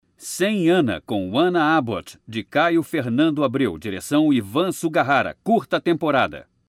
Masculino
Narração - Documentário Nova Sede do Flamengo
Voz Madura